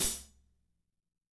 -16  HAT 3-R.wav